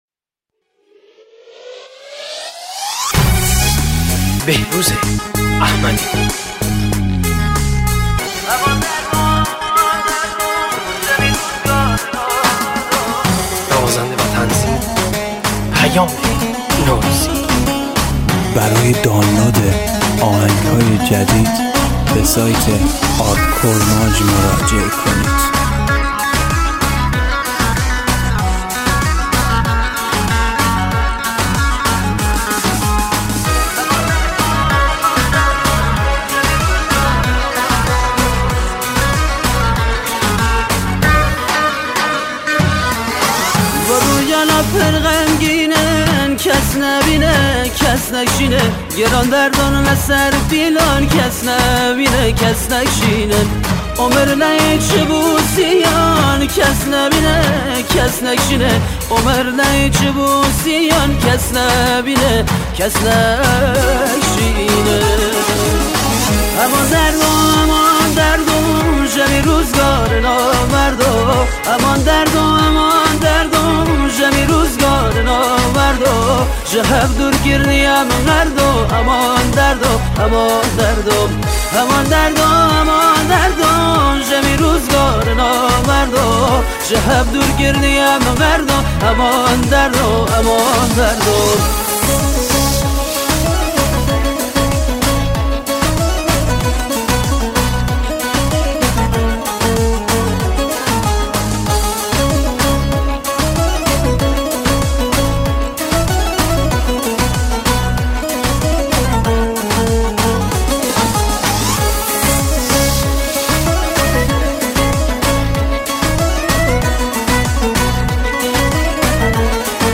آهنگ کرمانجی